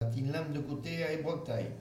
Localisation Sallertaine
Catégorie Locution